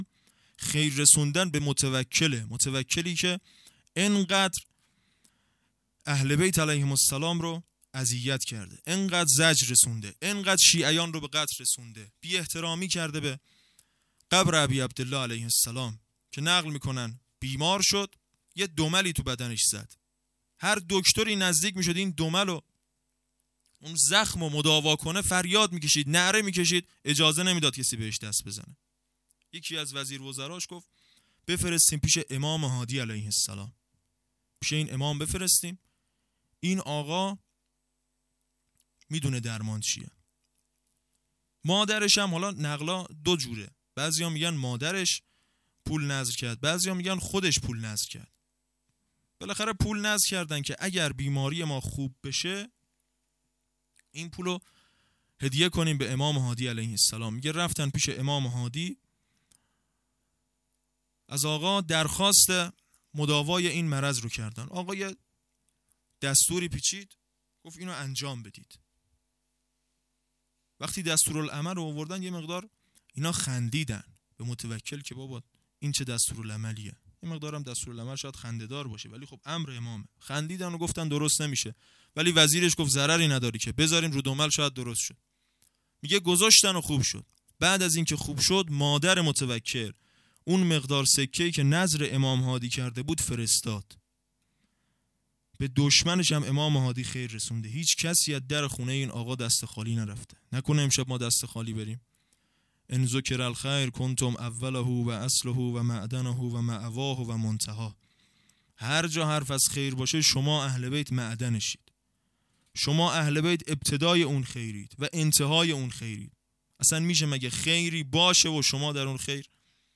هیئت معزالمومنین(علیه‌السلام) قم